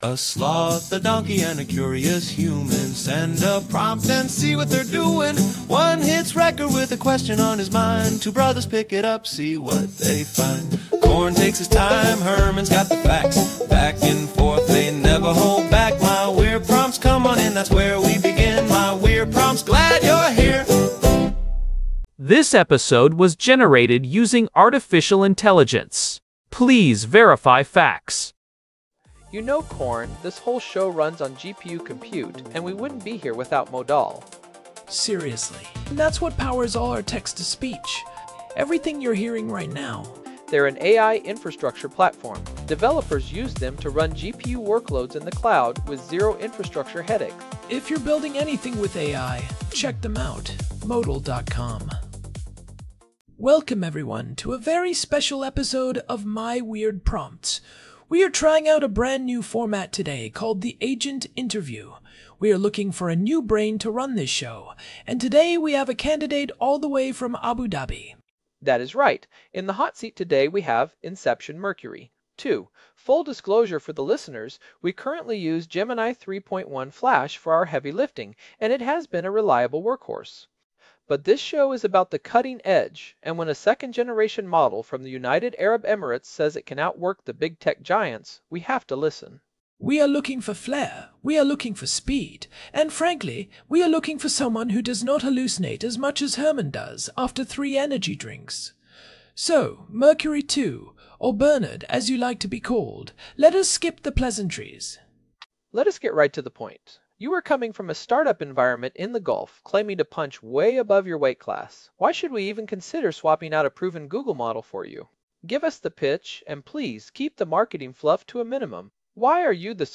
#1634: Agent Interview: Inception Mercury two
AI-Generated Content: This podcast is created using AI personas.